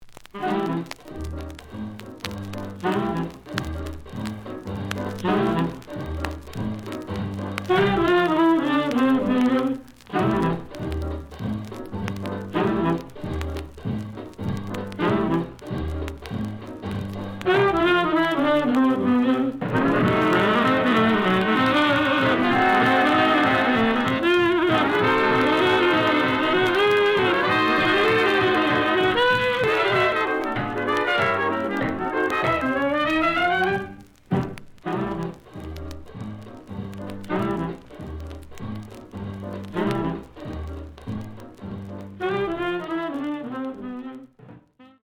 The audio sample is recorded from the actual item.
●Genre: Dixieland Jazz / Swing Jazz
Slight edge warp.